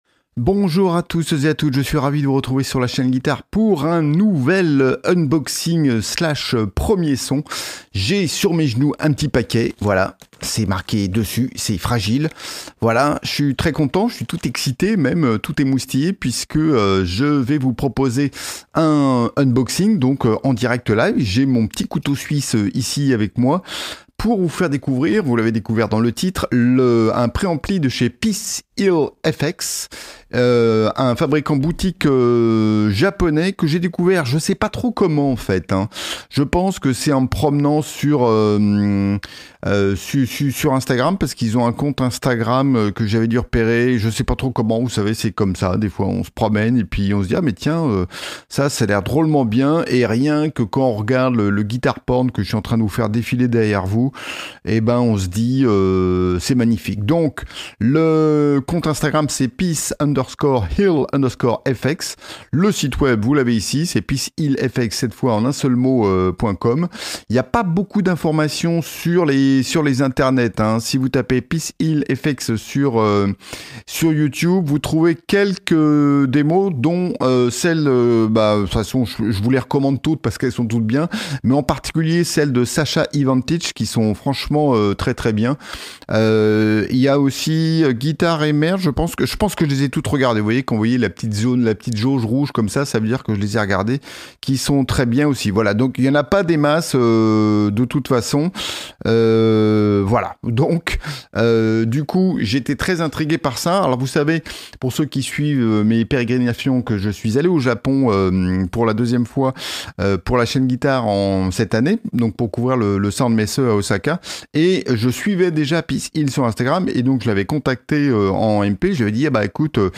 Peace Hill FX ODS Tube Preamp - Unboxing & Premiers sons
Voilà du super matos en unboxing et premiers tests ! Il s'agit d'un préampli à lampes ODS Tube Preamp de Peace Hill FX.